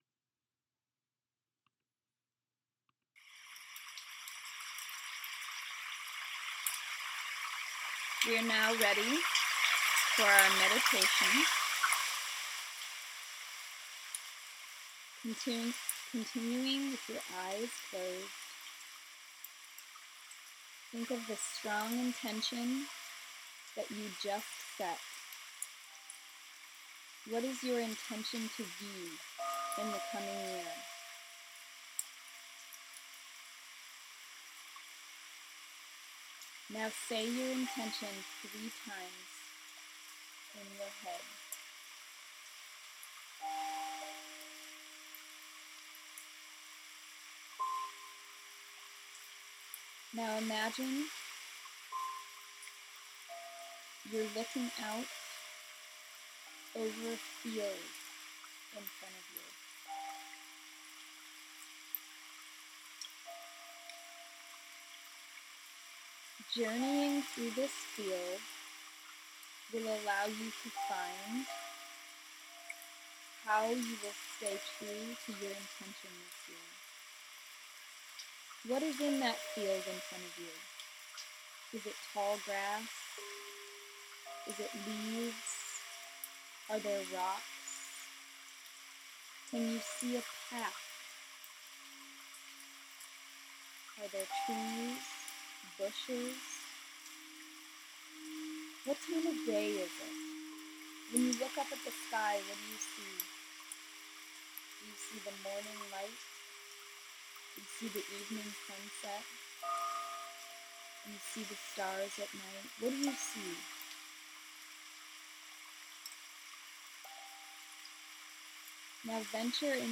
Part Two is aÂ 7 minute Visualization Meditation
Guided-Meditation-for-Setting-Intention-Part-2.m4a